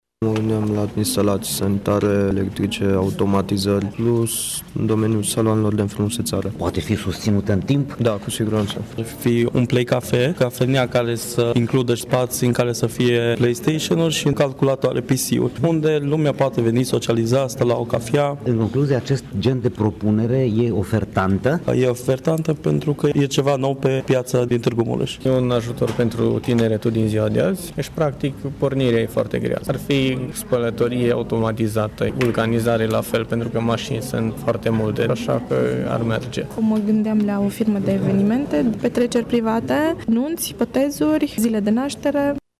Participanții la eveniment au apreciat ideea acestui tip de start-up în afaceri, iar majoritatea au în vedere businessuri în domeniul serviciilor: